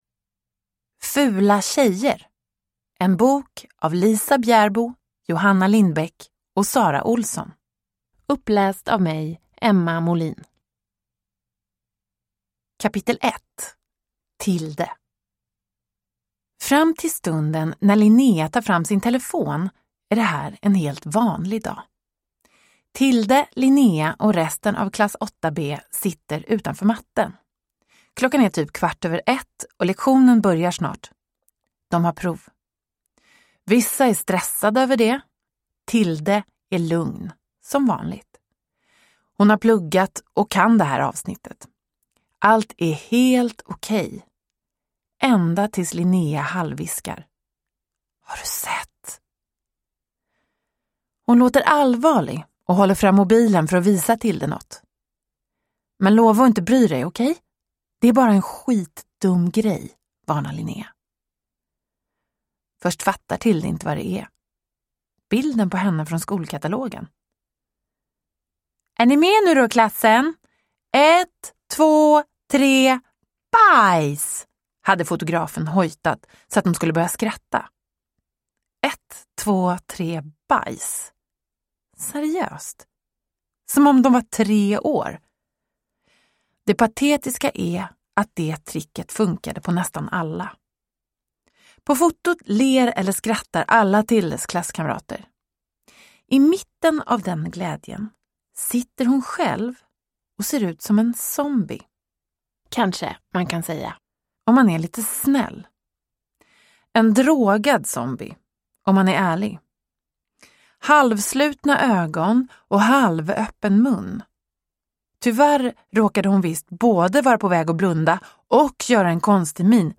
Fula tjejer – Ljudbok
Uppläsare: Emma Molin